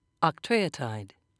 (oc-tre'o-tide)